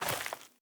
added stepping sounds
DirtRoad_Mono_04.wav